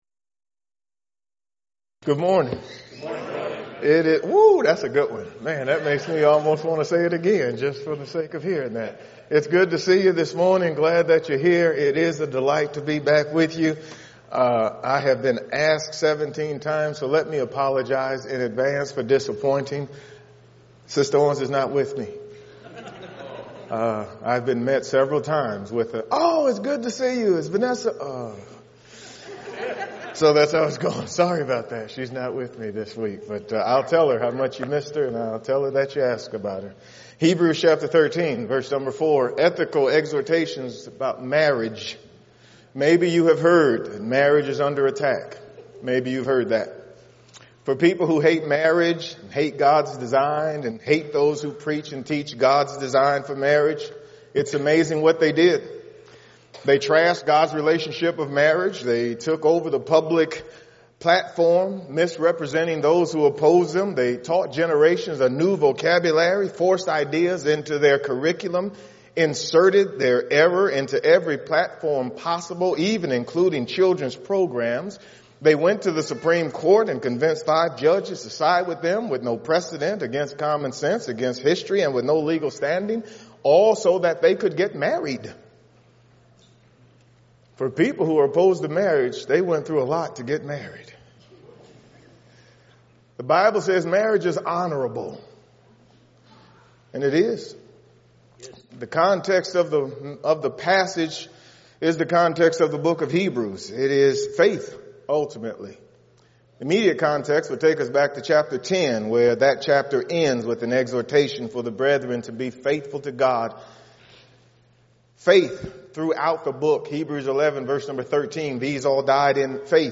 Event: 2019 Focal Point
If you would like to order audio or video copies of this lecture, please contact our office and reference asset: 2019FocalPoint21